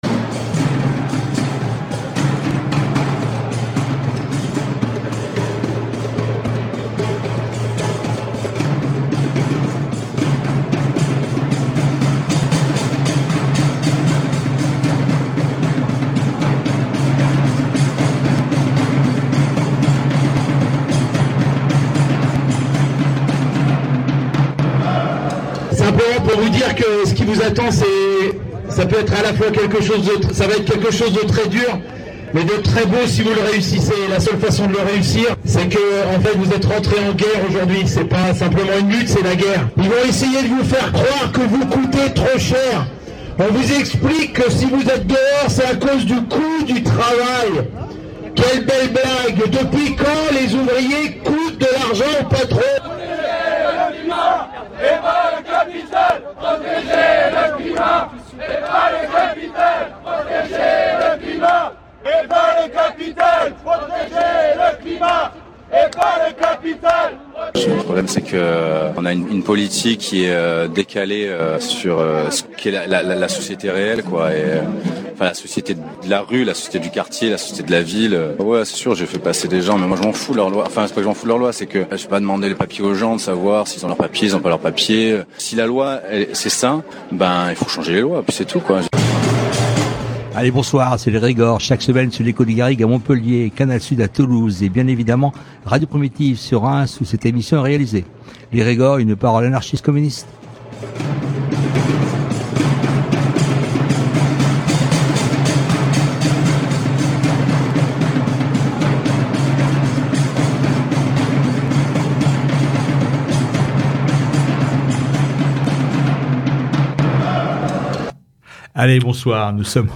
Comme promis la semaine dernière, nous revenons sur la journée du 18 décembre, journée internationale de solidarité avec les migrants, avec la diffusion d’extraits des prises de paroles lors de l’assemblée qui se tenait place de la République juste avant la manifestation parisienne.